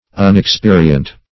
Unexperient \Un`ex*pe"ri*ent\, a.